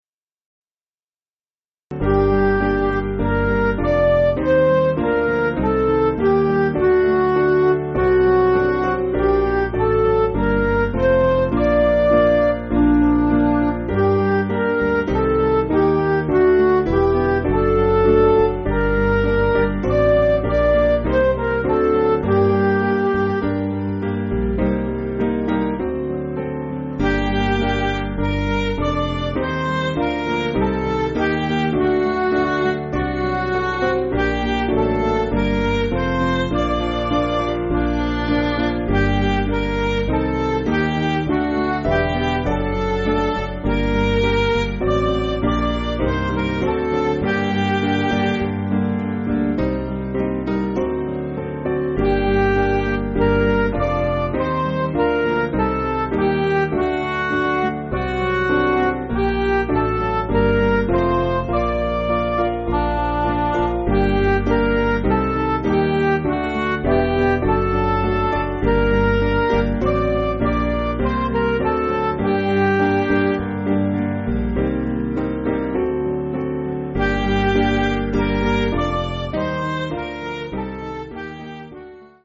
Piano & Instrumental
(CM)   6/Gm